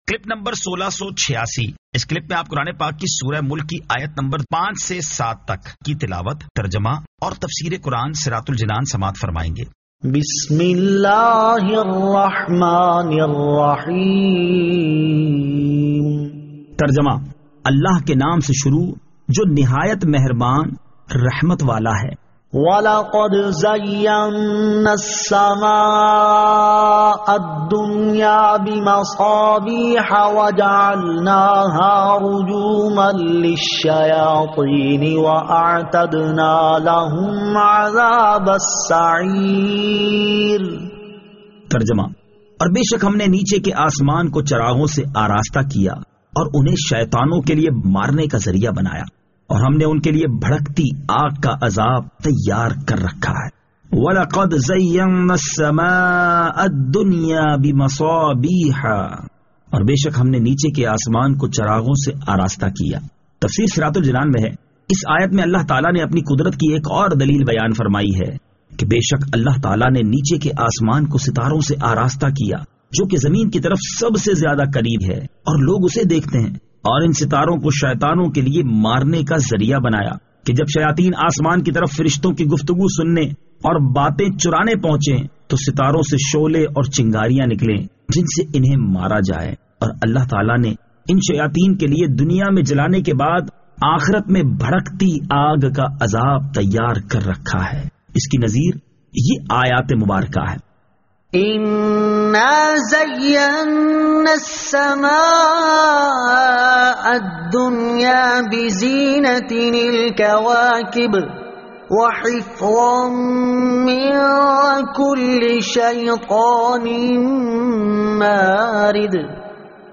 Surah Al-Mulk 05 To 07 Tilawat , Tarjama , Tafseer